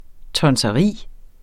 Udtale [ tʌnsʌˈʁiˀ ]